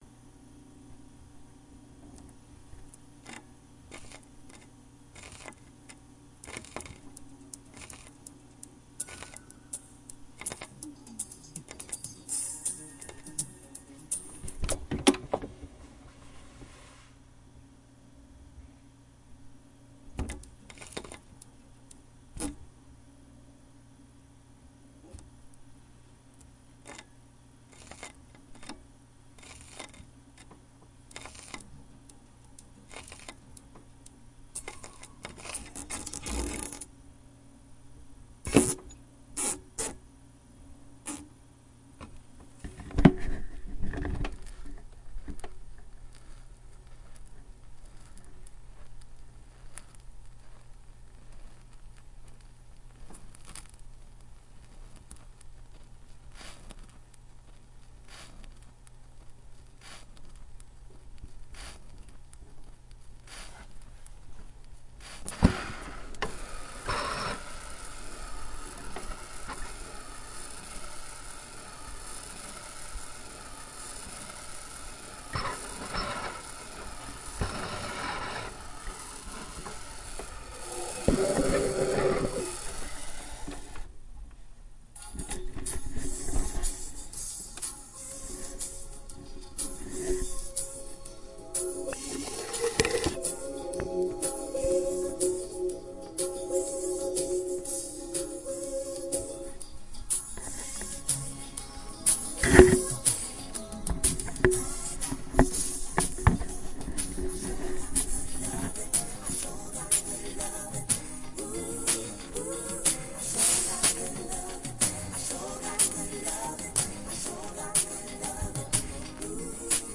唱片黑胶播放划痕
描述：记录乙烯基玩搔抓
Tag: 播放 乙烯基 记录 搔抓